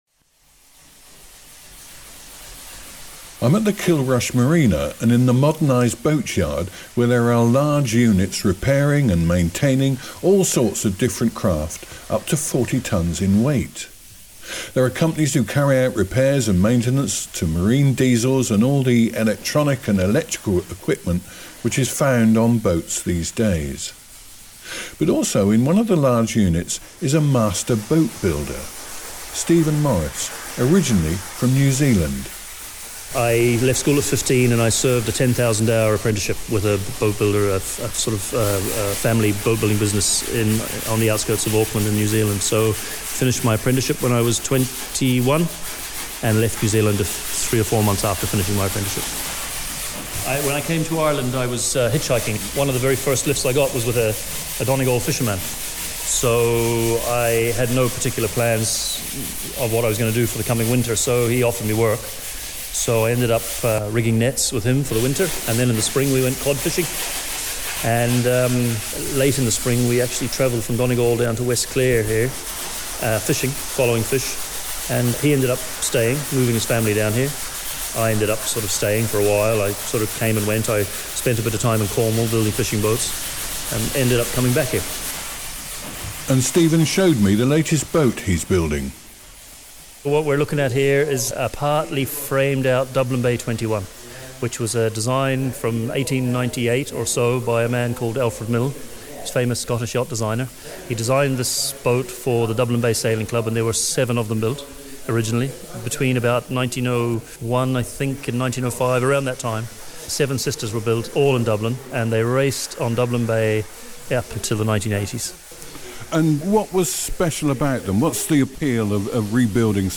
in Kilrush Marina